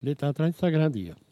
Localisation Notre-Dame-de-Monts
Catégorie Locution